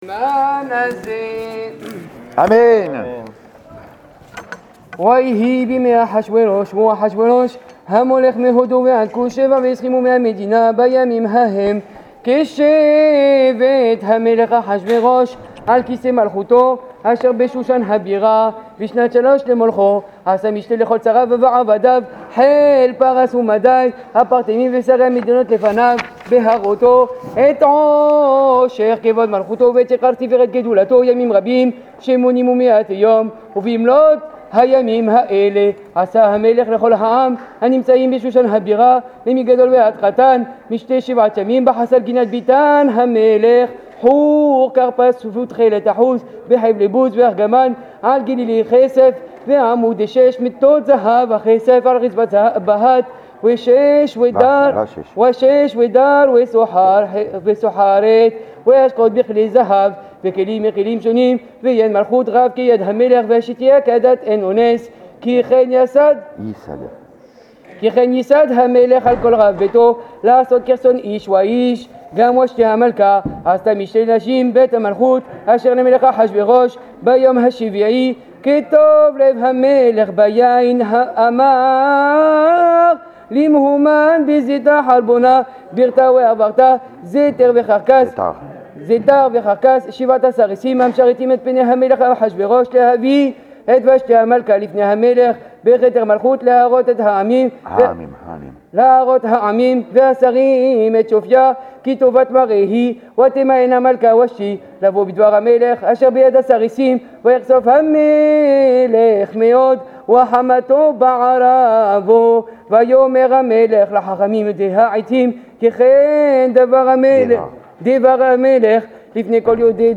Pourim 5774 chez Rebbi Hai Taieb Lo Met ...en direct - Synagogue Rebbi Hai Taieb Lo Met
Lectures de la Meguila